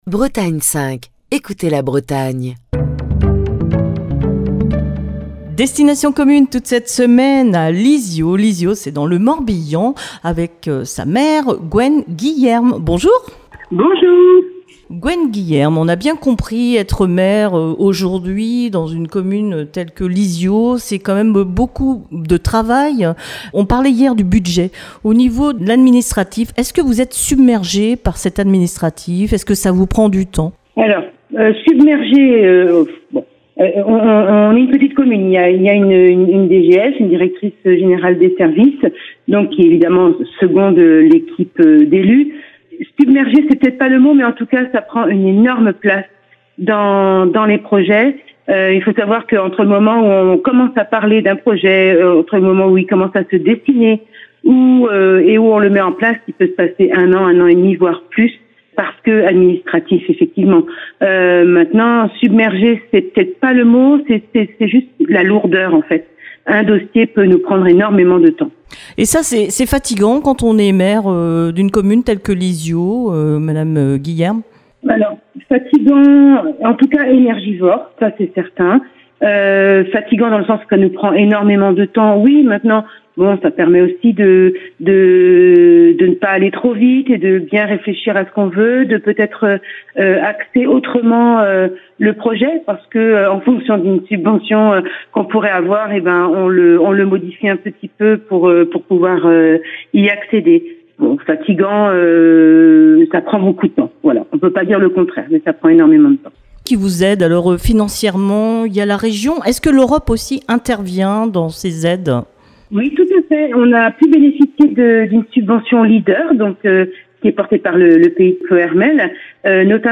Cette semaine, Destination commune s'arrête à Lizio, dans le Morbihan. Gwen Guillerme, la maire de Lizio, vous propose de découvrir sa commune à travers les initiatives locales, les relations avec les habitants, mais aussi sa charge de maire et les projets pour l'avenir